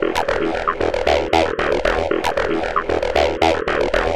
Помогите, пожалуйста, как накрутить такой арп бас?